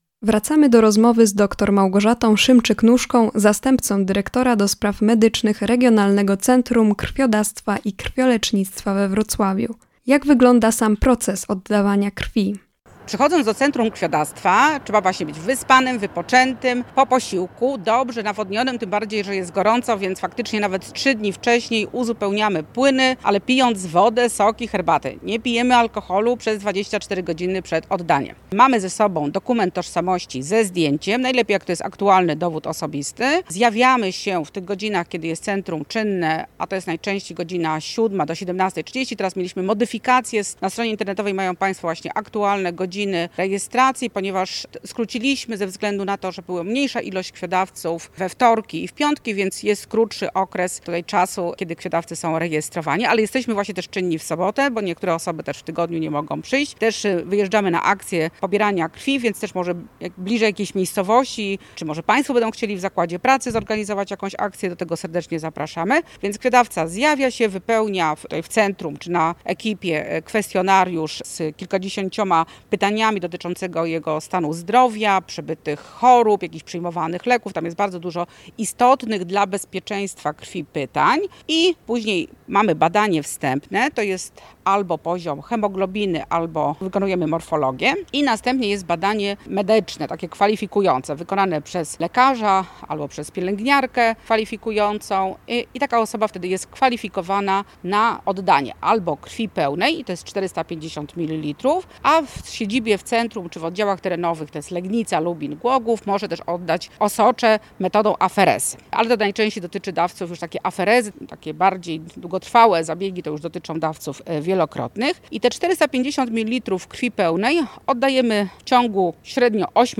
rozmowa-cz.2.mp3